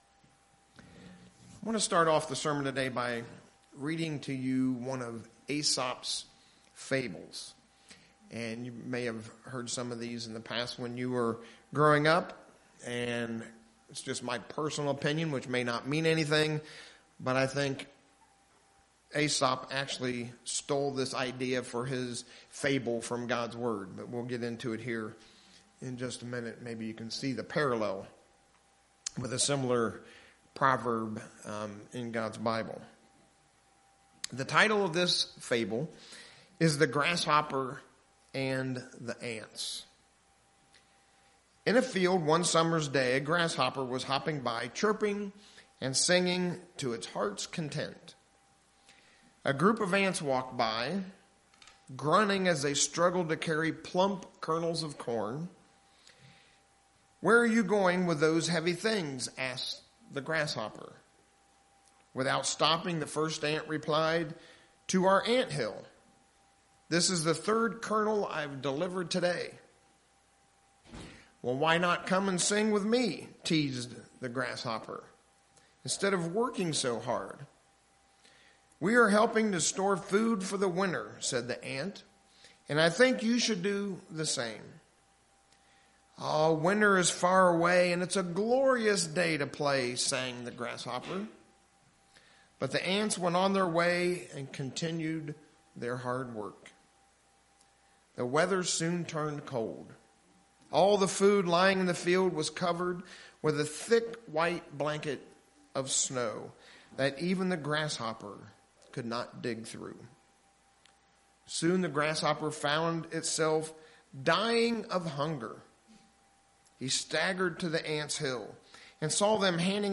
Having a relationship with God requires that we develop certain disciplines. In the sermon today we will look at 3 spiritual disciplines that will help strengthen our place in Christ.
Given in Lehigh Valley, PA